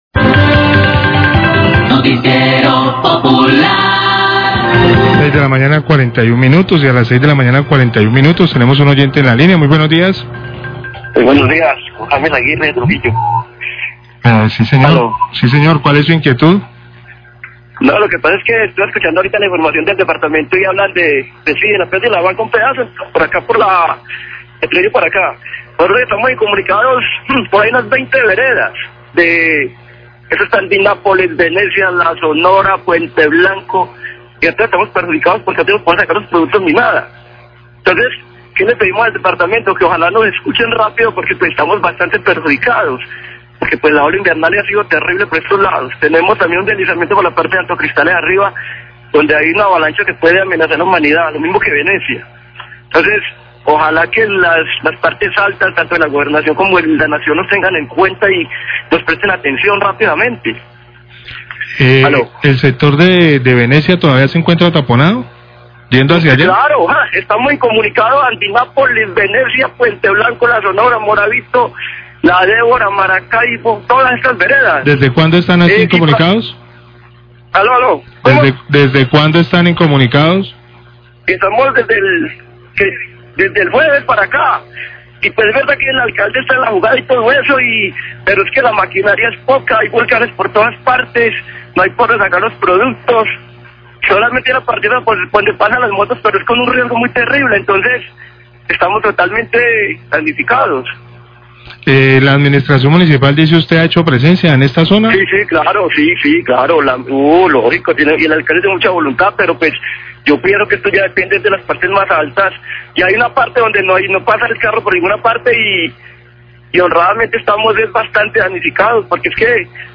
Radio
Oyente